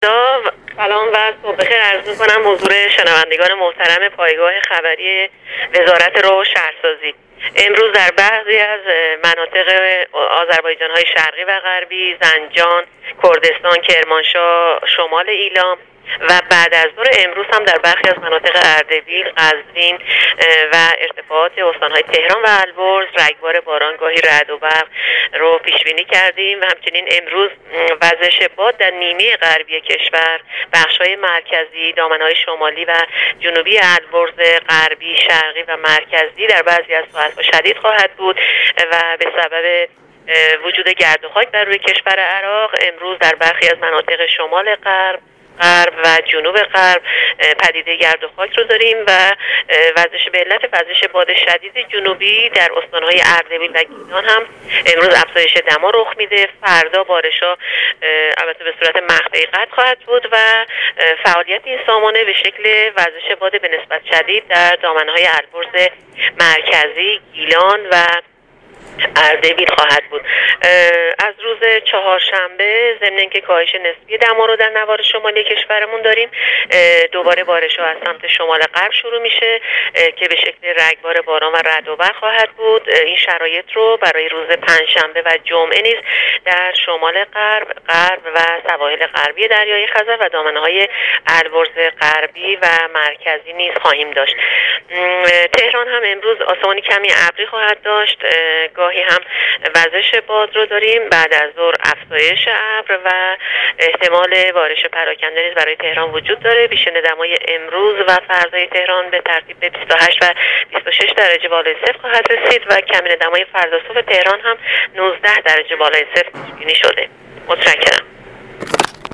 در گفتگو با راديو اينترنتی پايگاه خبری